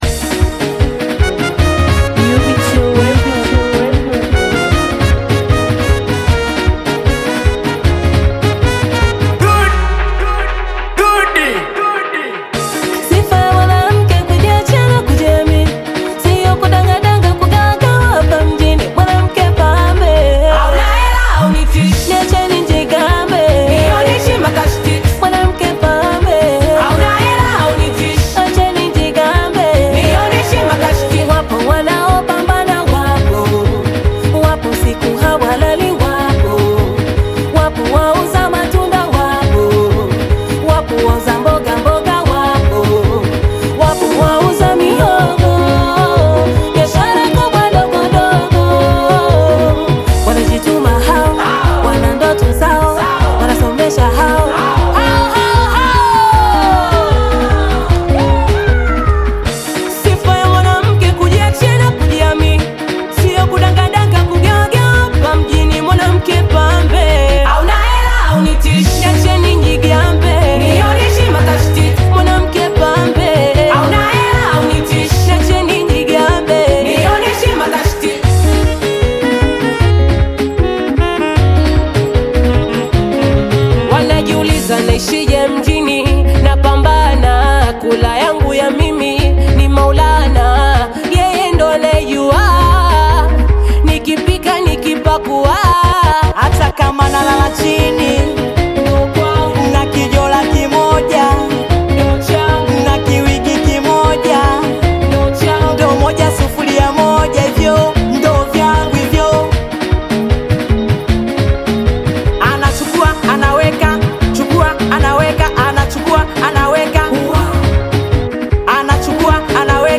Singeli music track
Tanzanian Bongo Flava music group